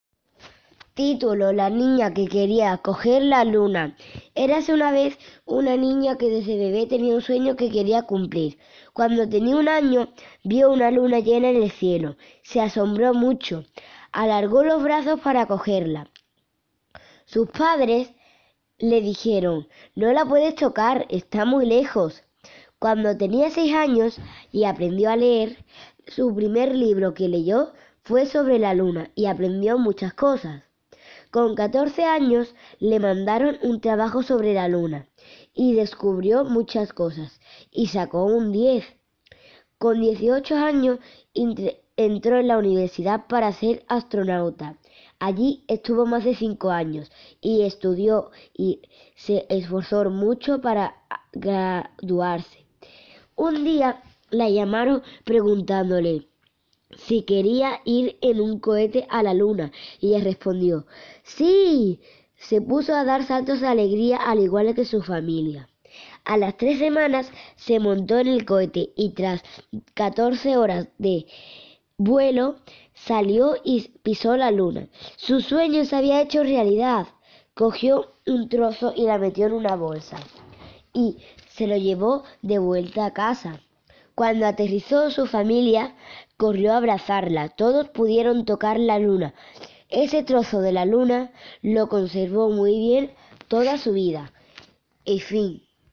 cuento